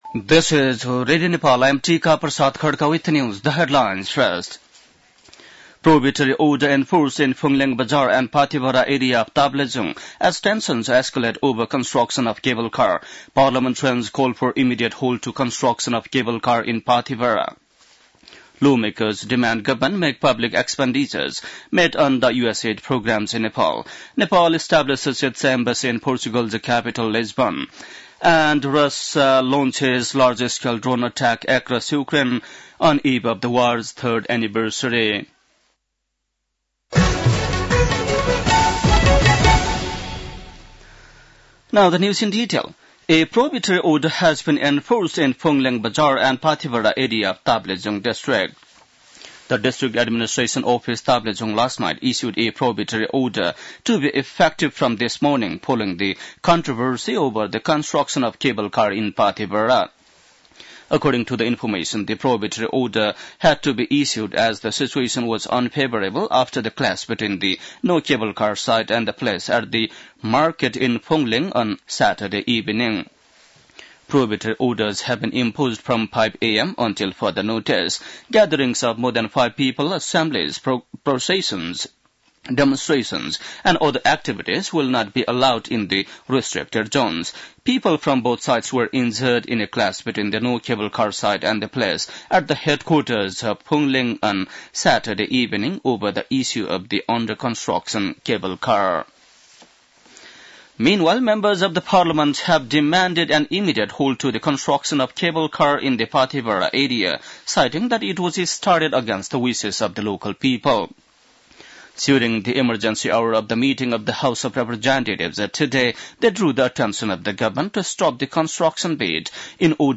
बेलुकी ८ बजेको अङ्ग्रेजी समाचार : १२ फागुन , २०८१
8-pm-english-news-11-11.mp3